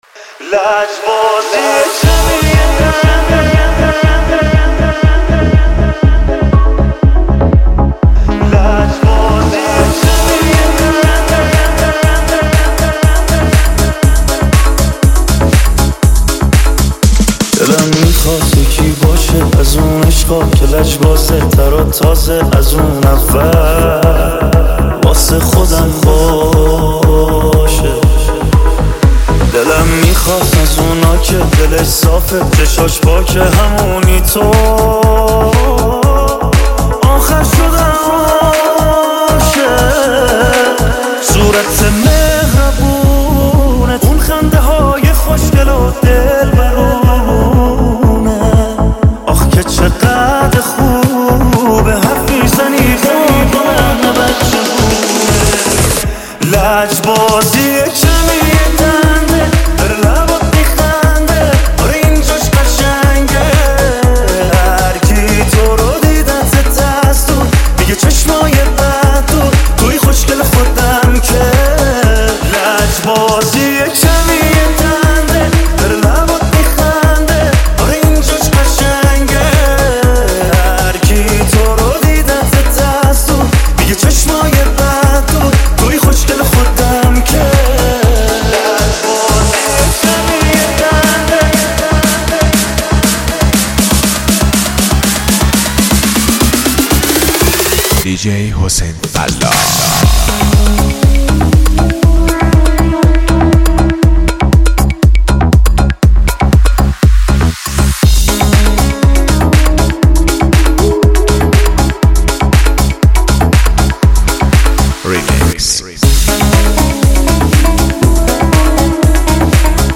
آهنگهای پاپ فارسی
ریمیکس